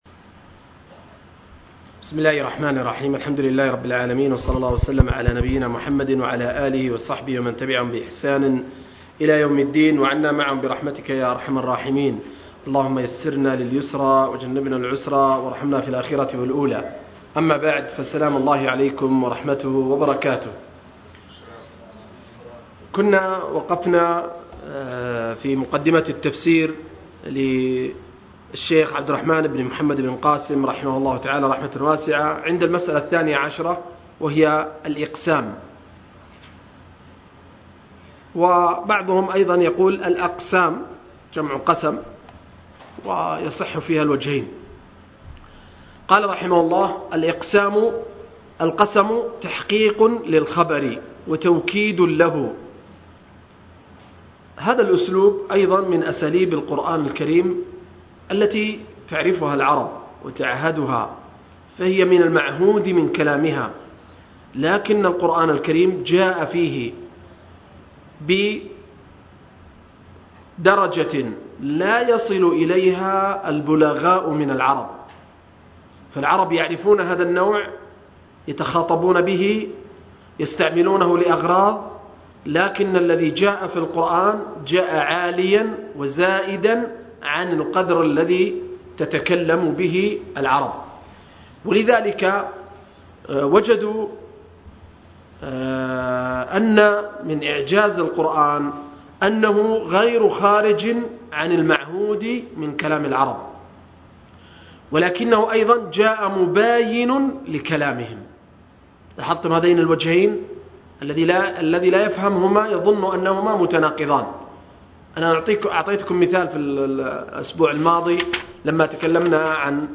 05-مقدمة التفسير للشيخ ابن قاسم رحمه الله – الدرس الخامس